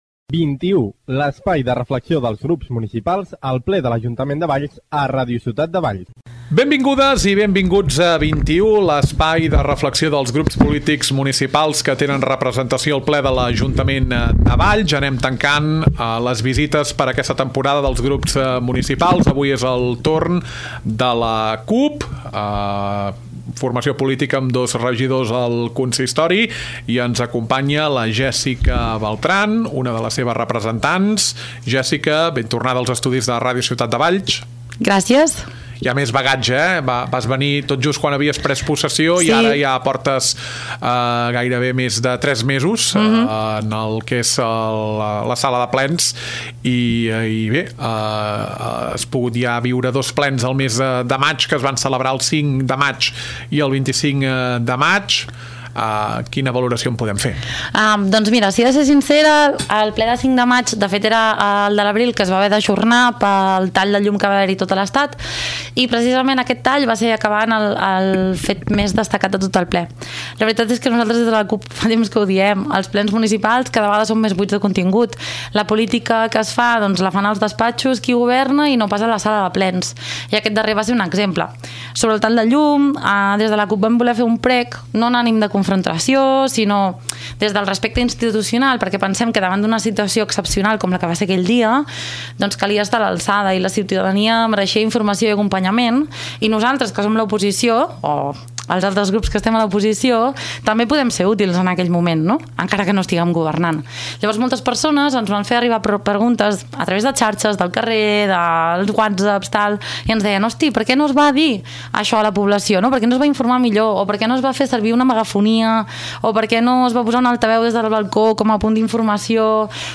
Avui és el torn de la CUP, grup municipal de l’oposició amb dos representants. Conversa amb la regidora, Jèssica Beltran